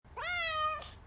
cat-4r_NkDcK.ogg